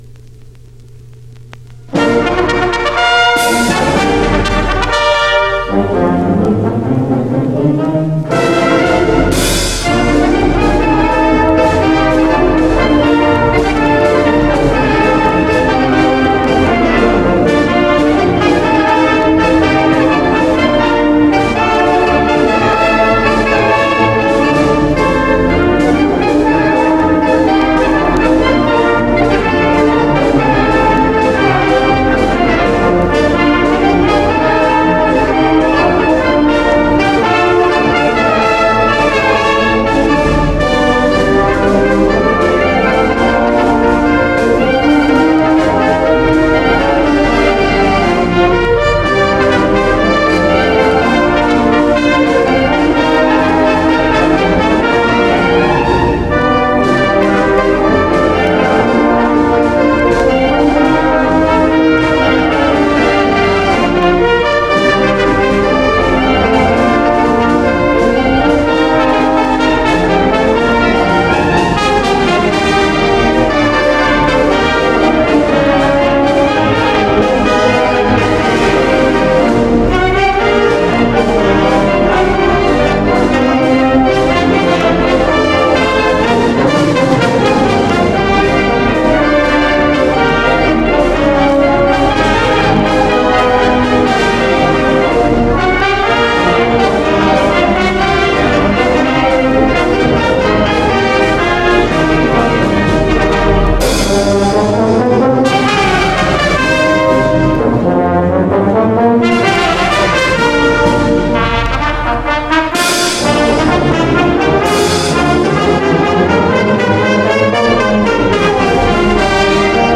Be sure to listen to the band play the fight song, alma mater, and a few other familiar musical pieces.
Marching Band